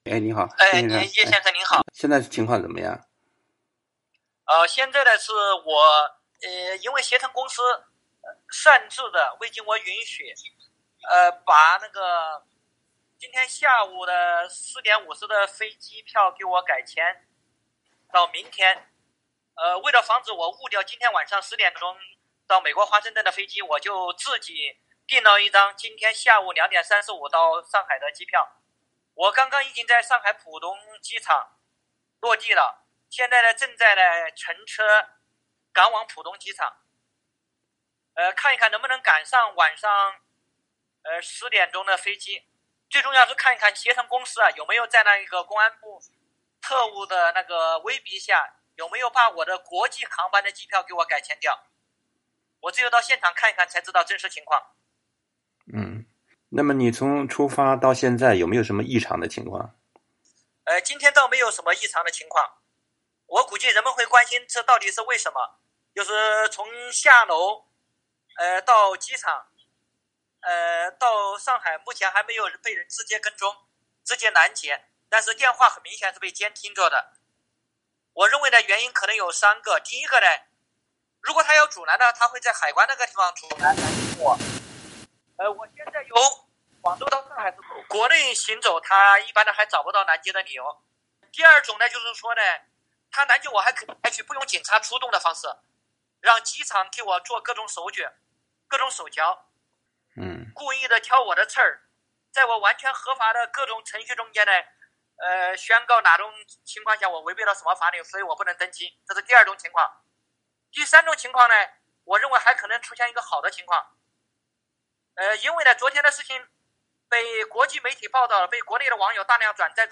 美国之音记者稍早前与郭飞雄作了电话采访，当时他们正在前往浦东机场。